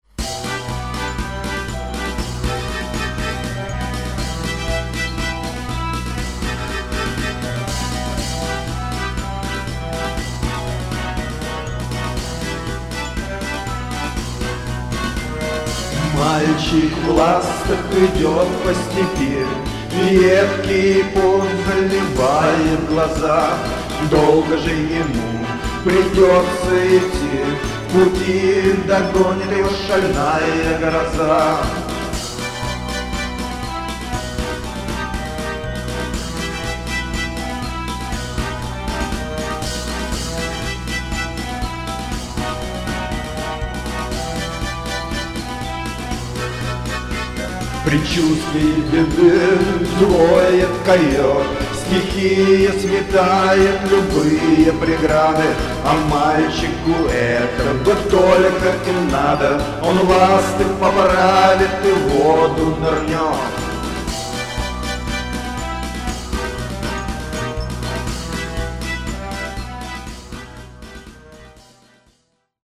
Записей у группы гораздо больше, хотя в основном они (альбом "Засада" - исключение) так и остаются в черновом варианте.